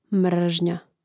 mŕžnja mržnja